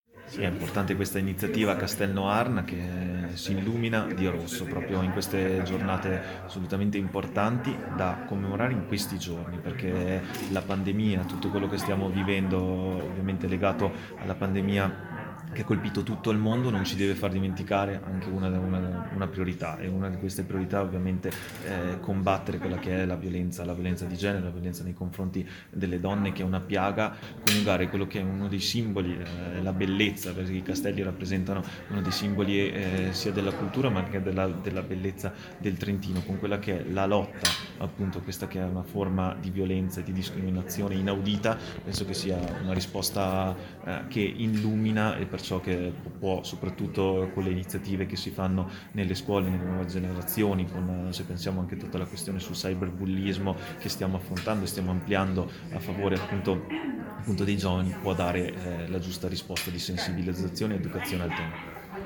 Bisesti_Noarna_iniziativa_contro_violenza.mp3